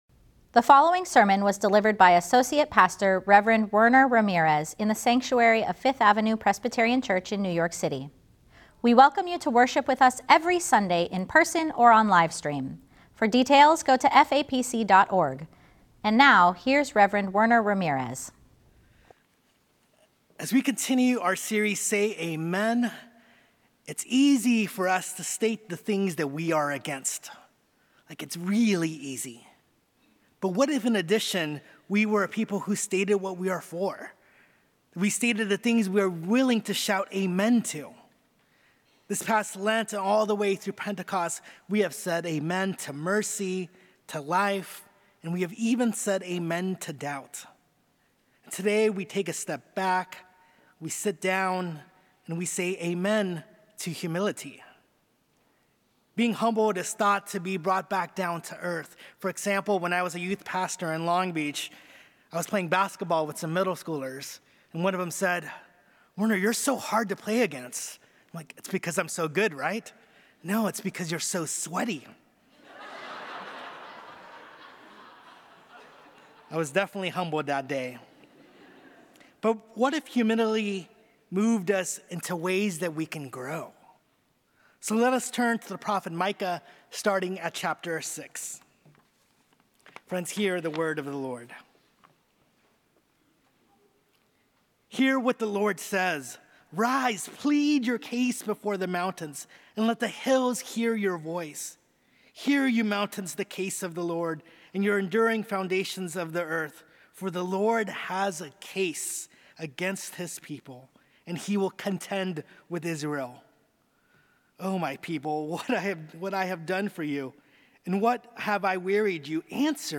Sermons at FAPC
Easter Sunday